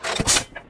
b92Reload.ogg